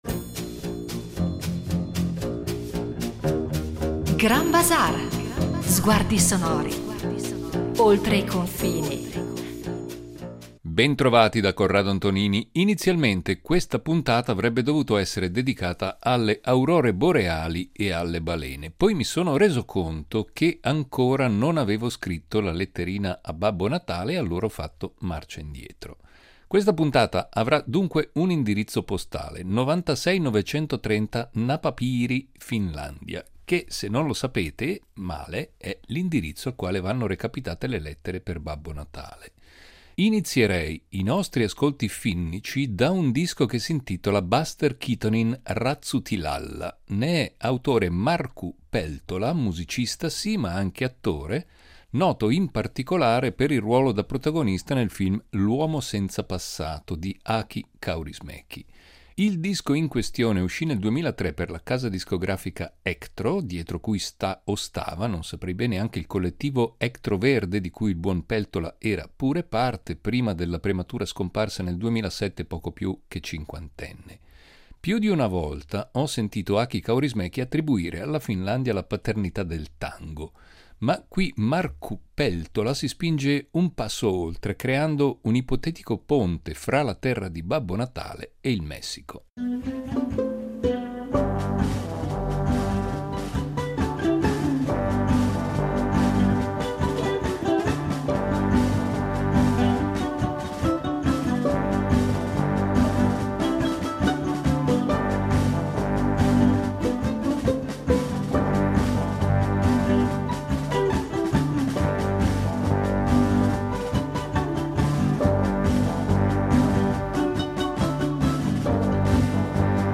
Il grande freddo , più che un auspicio o una reminiscenza nostalgica, sarà il tentativo di evocare le melodie e i canti coi quali le popolazioni del Grande Nord da sempre affrontano il rigore delle stagioni a quelle latitudini. Islanda, Groenlandia, il Canada, la Lapponia, la Siberia, la Mongolia…